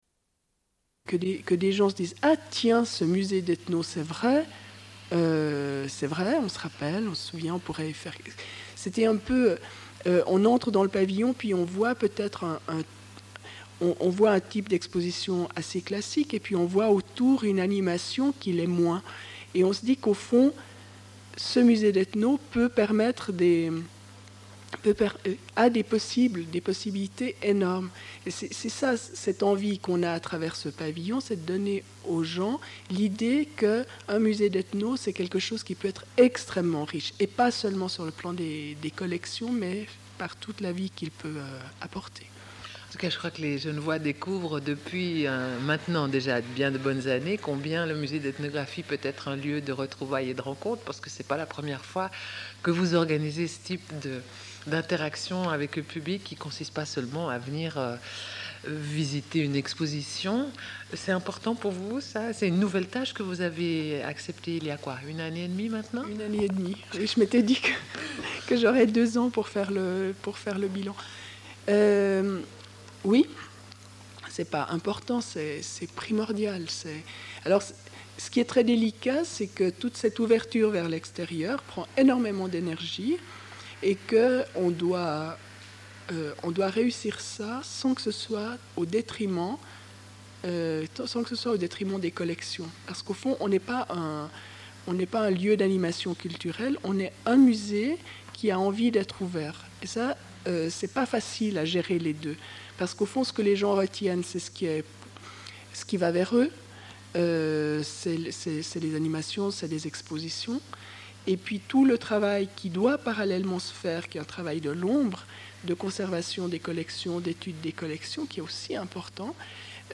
Une cassette audio, face B29:10